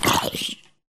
zombiehurt1.ogg